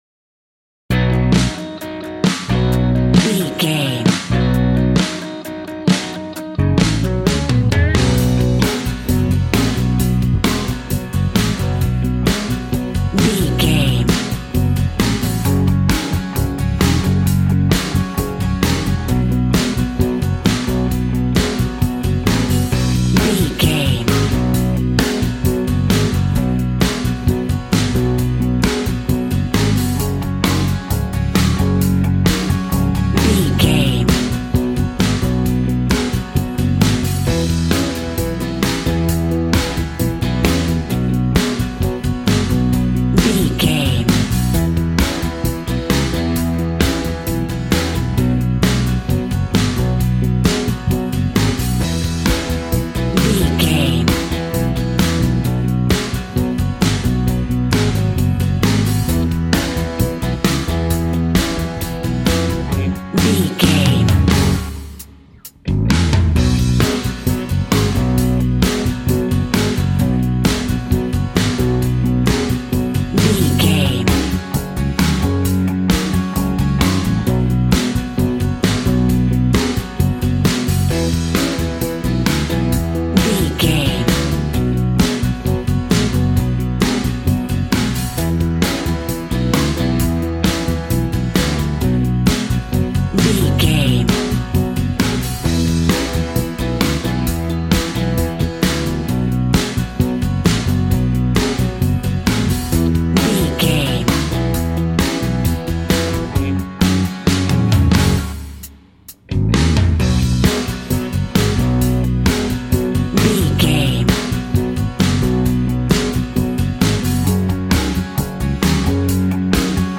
Ionian/Major
hard
groovy
powerful
electric guitar
bass guitar
drums
organ